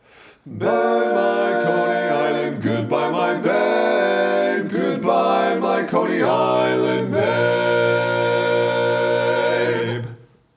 Key written in: B♭ Major
Type: Barbershop